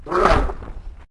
Heroes3_-_Crimson_Couatl_-_DefendSound.ogg